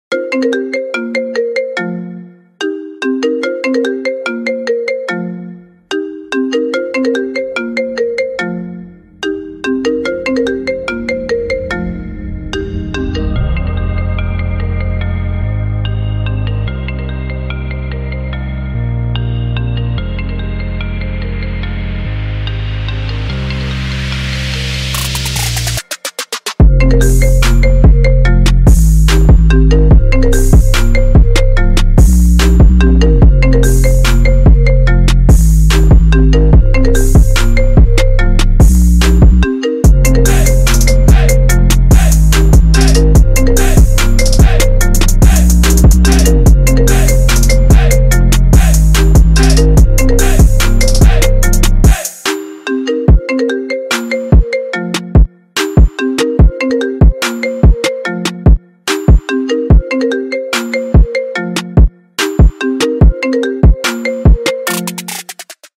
remix lại trên nền nhạc EDM sôi động